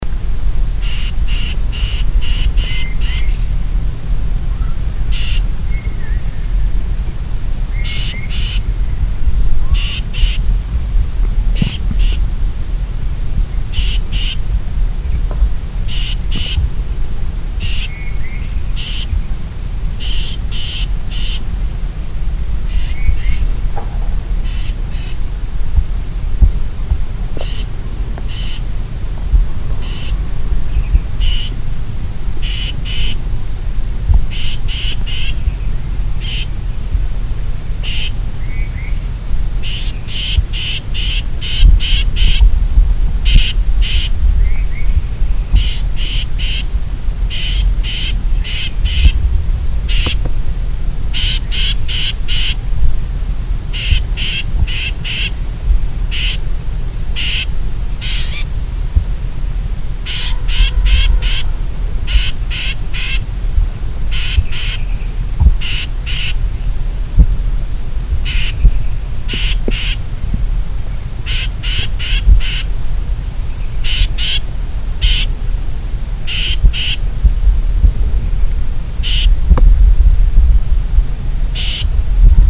Cris d’alarme enregistrés le 05 février 2012, en Chine, province du Guangxi, à Shanli près de la ville de Yizhou.